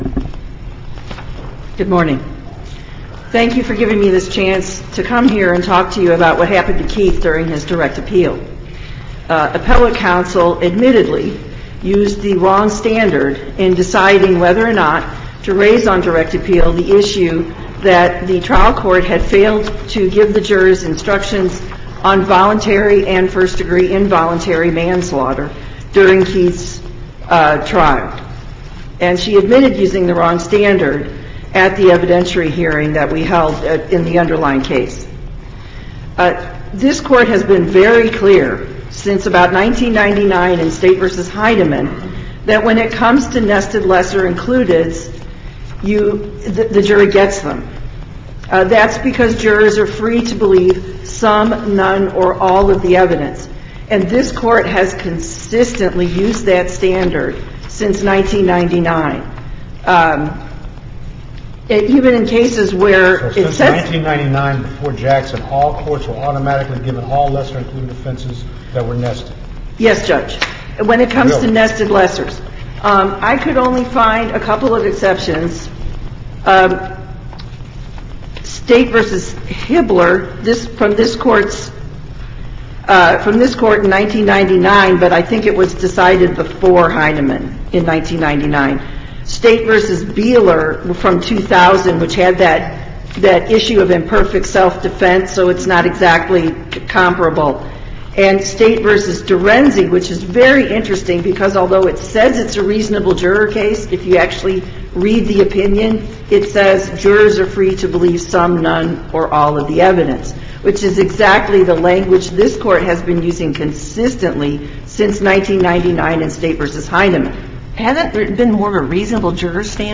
Challenge to denial of variance for digital signage Listen to the oral argument